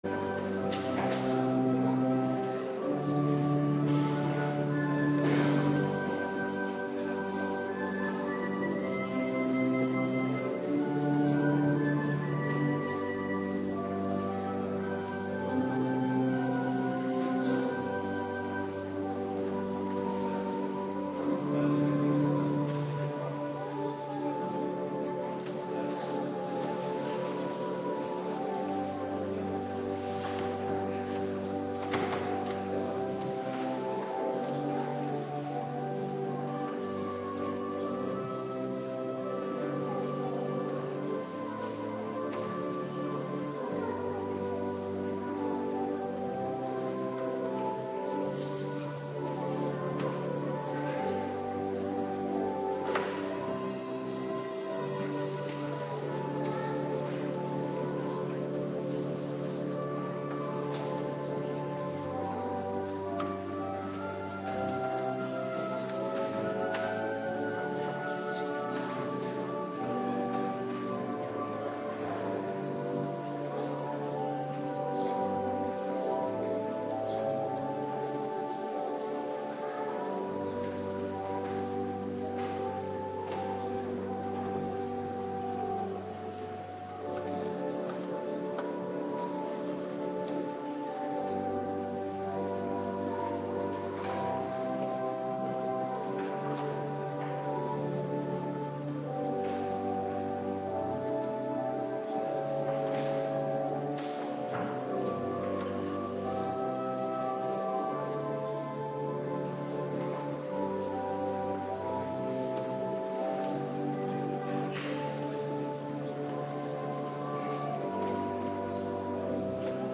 Morgendienst Bevestiging ambtsdragers - Cluster 1
Locatie: Hervormde Gemeente Waarder